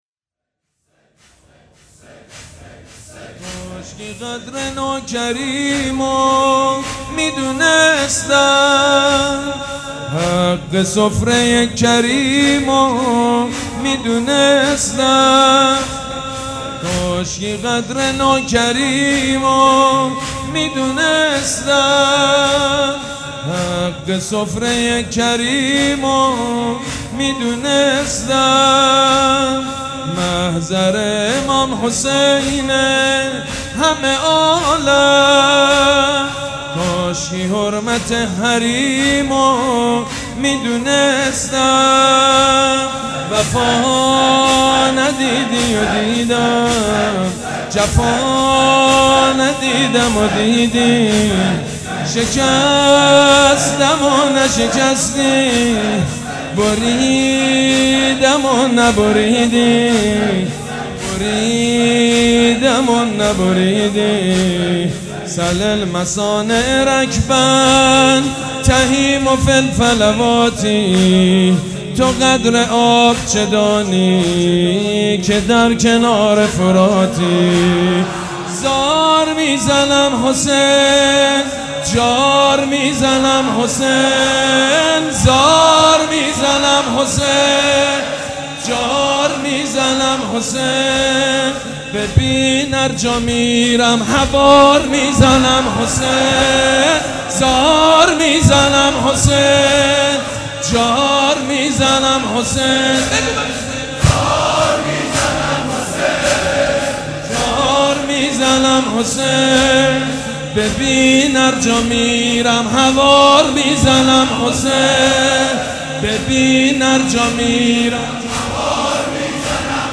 مداح
حاج سید مجید بنی فاطمه